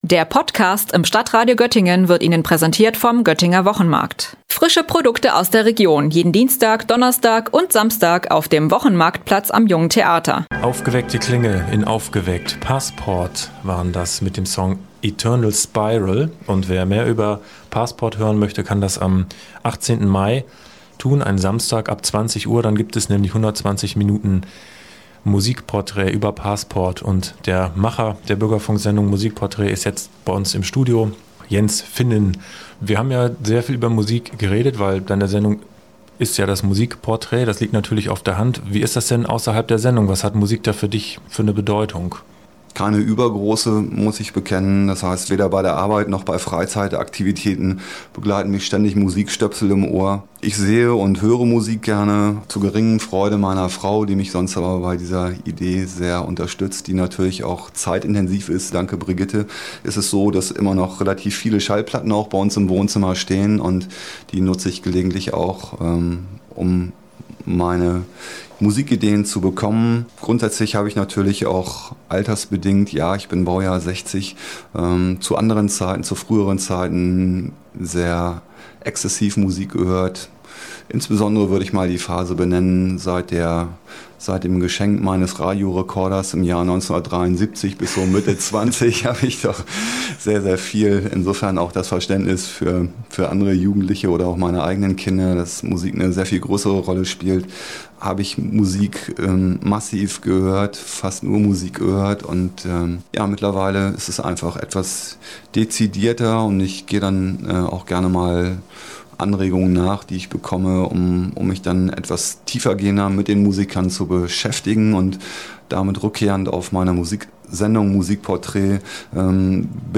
Heute spricht